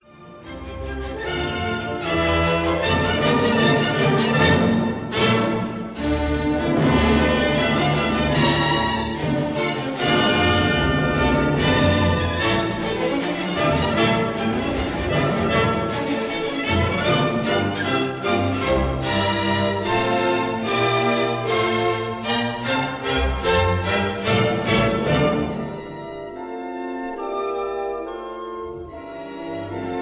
Recorded 27 March 1937 in No. 1 Studio,
Abbey Road, London